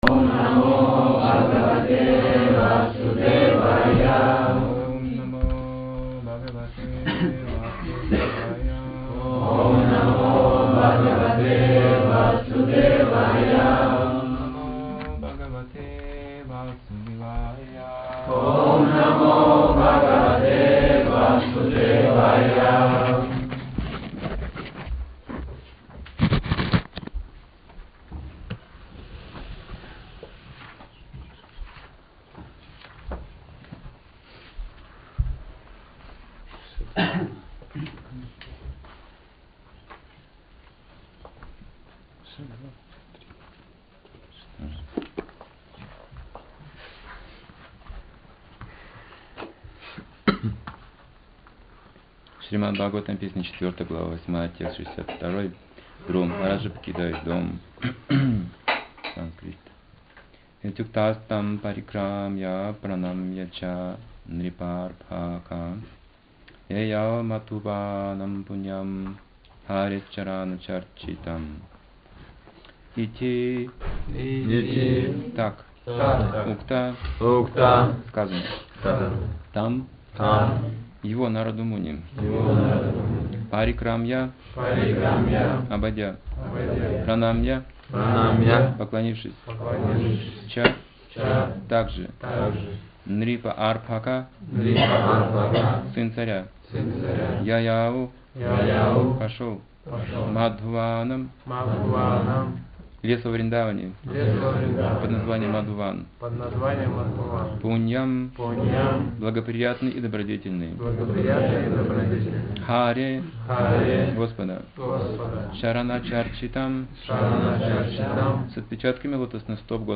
Темы, затронутые в лекции: